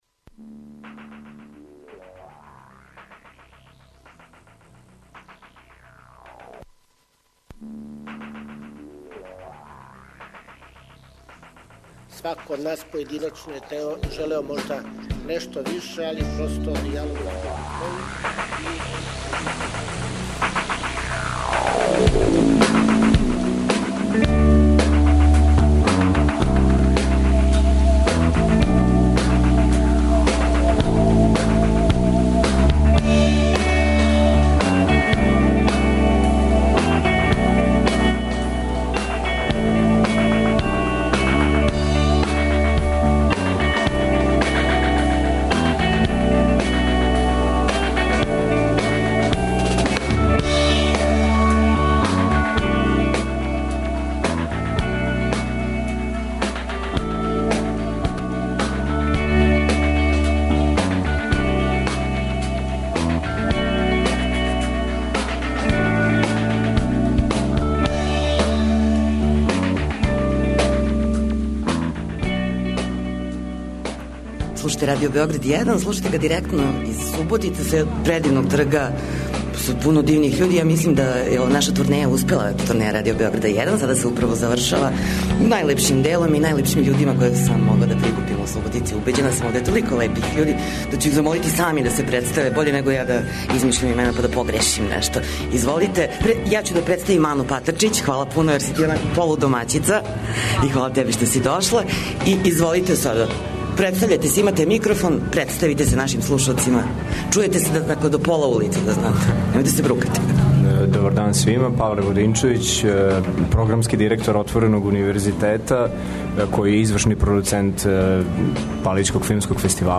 Говоримо о уметничком и културном животу у Суботици, пошто данашњу емисију реализујемо из овог града.